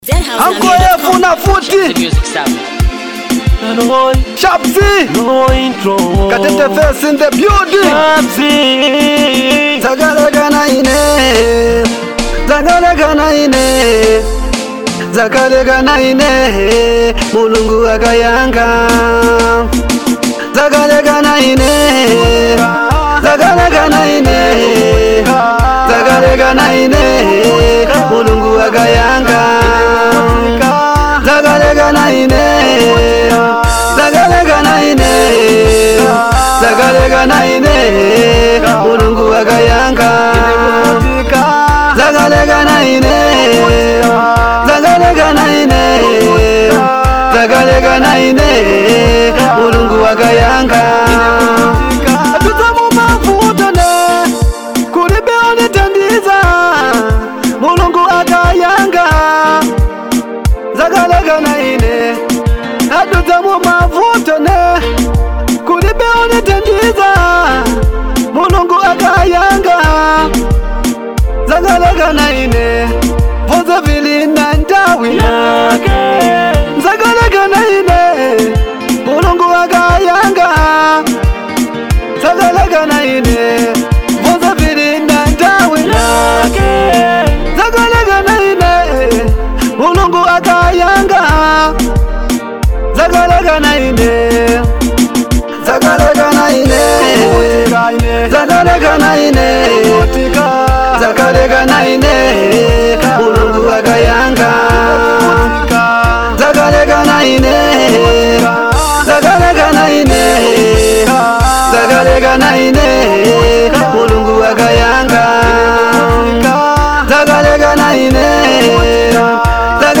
motivational anthem